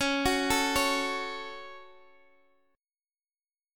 Listen to Dbsus4#5 strummed